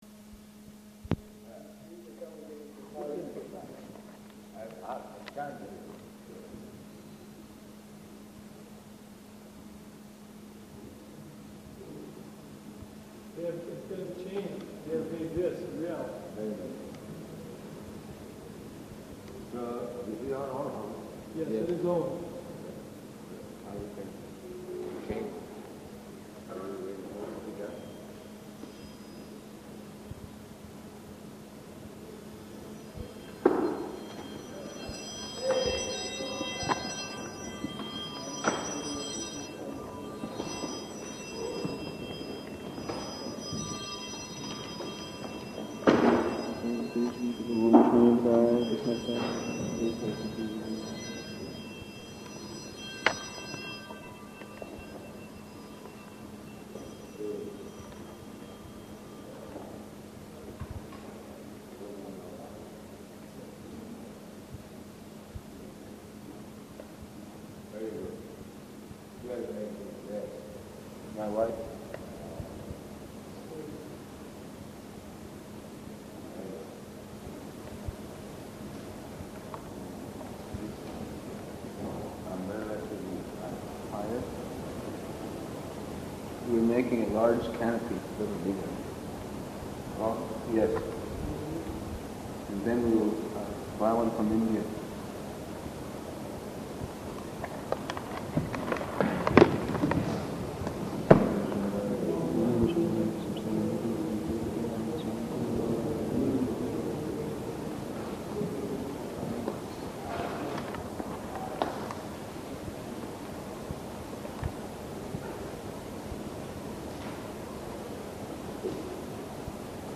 Location: Berkeley